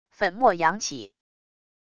粉末扬起wav音频